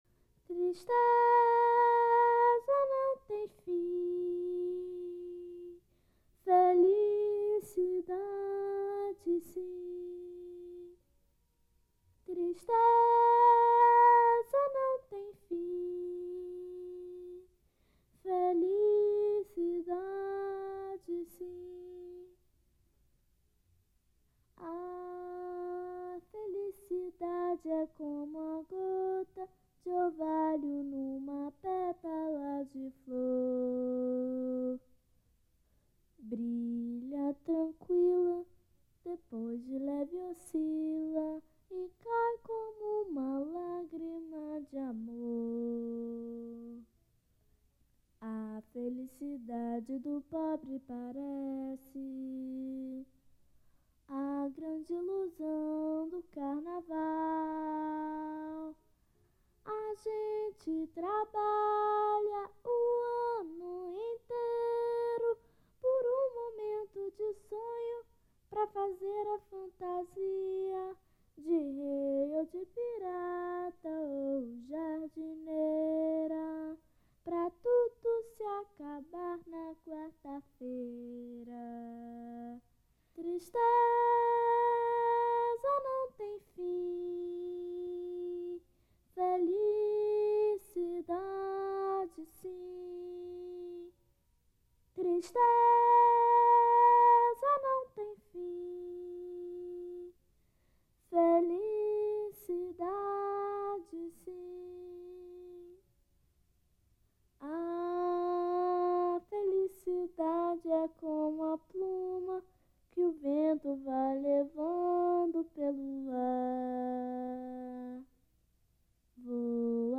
a capella
Type: Gravação musical